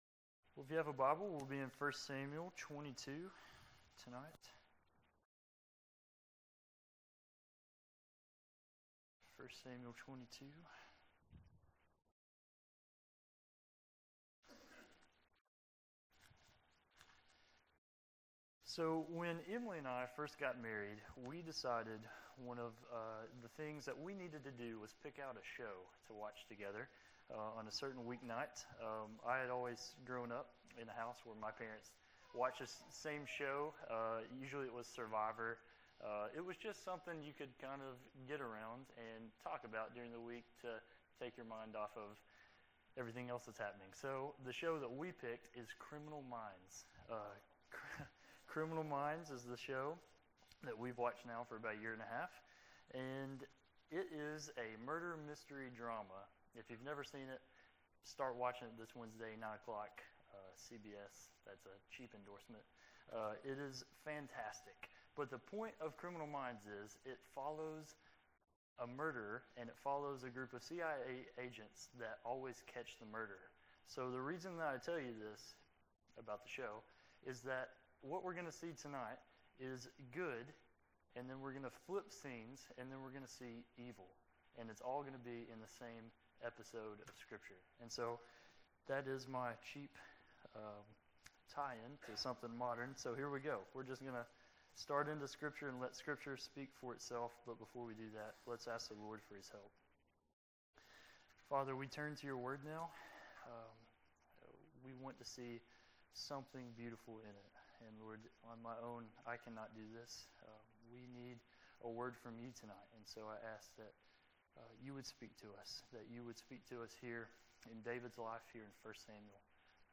This was the next message in multi-part sermon series on the book of 1st Samuel.